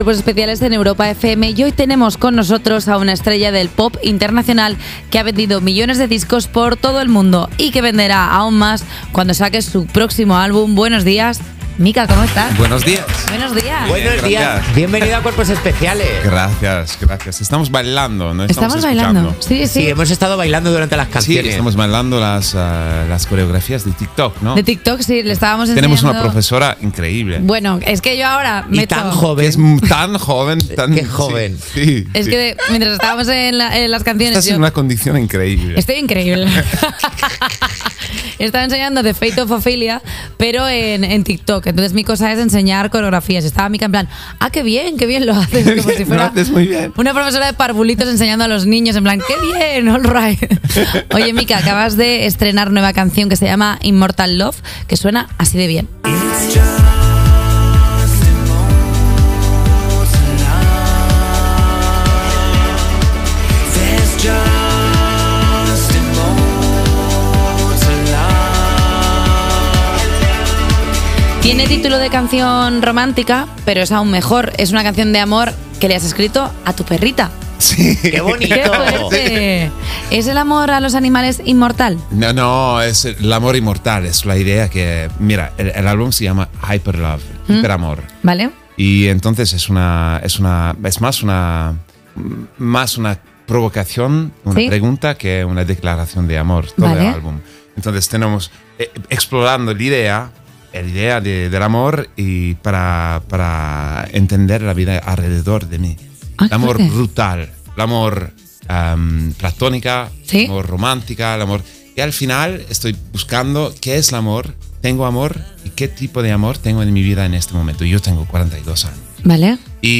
La entrevista de Mika en Cuerpos especiales